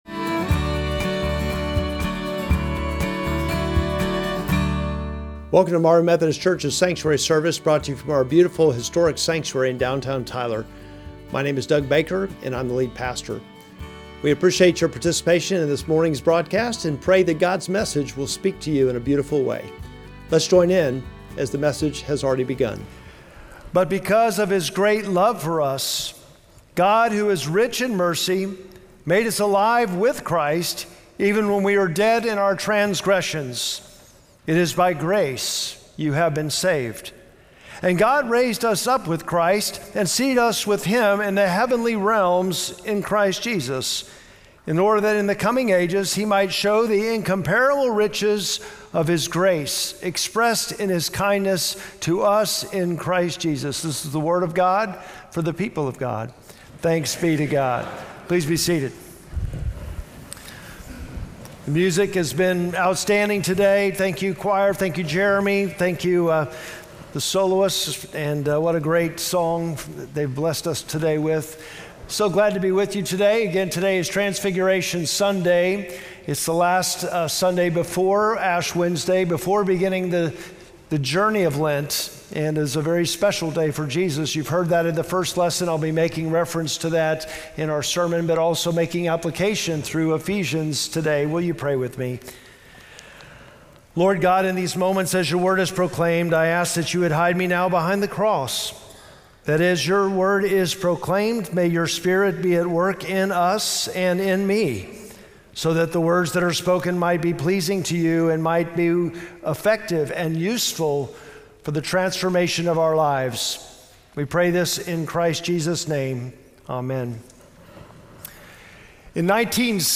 Sermon text: Ephesians 2:4-7